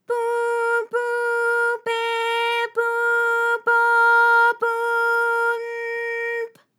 ALYS-DB-001-JPN - First Japanese UTAU vocal library of ALYS.
pu_pu_pe_pu_po_pu_n_p.wav